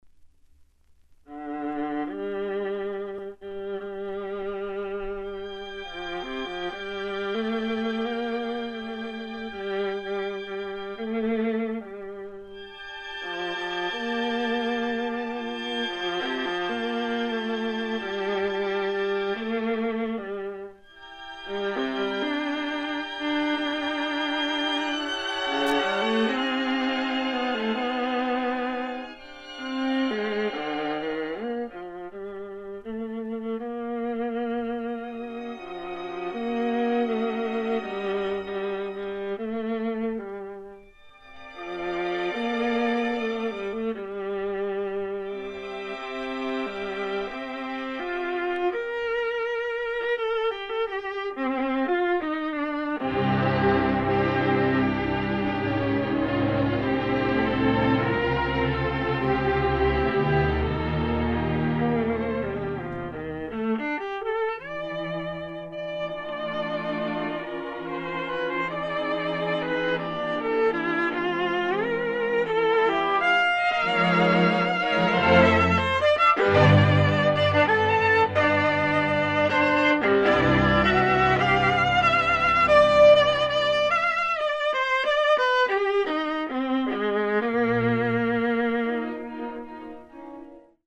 Viola